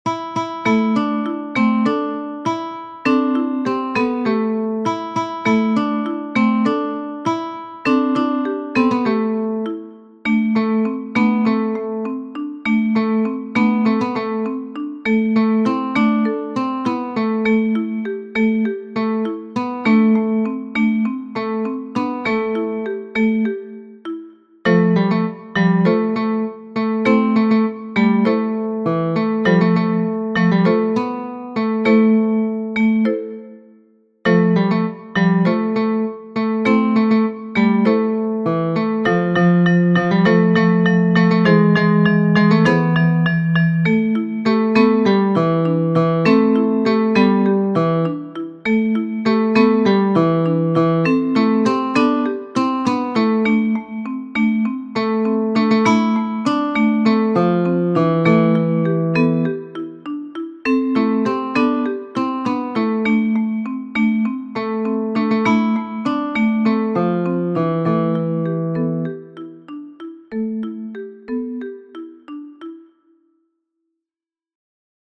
-The metallophone accompaniment performs... an ostinato